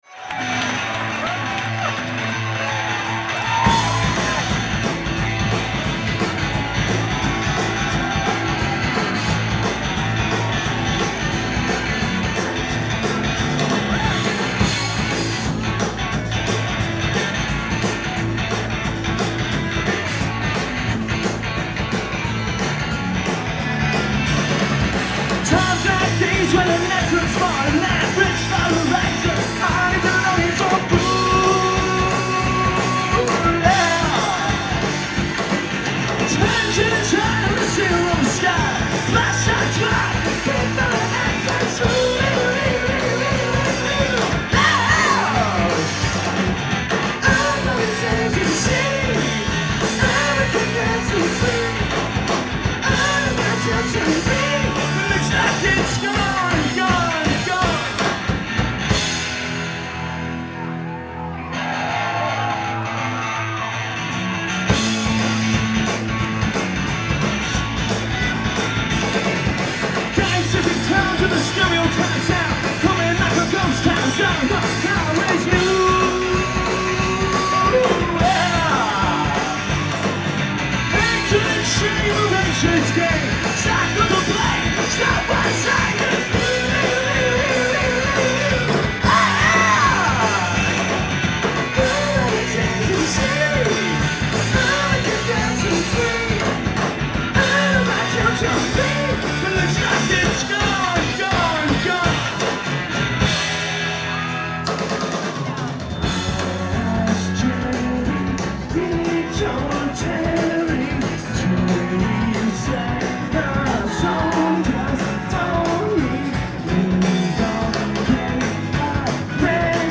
live at the Middle East, Cambridge, MA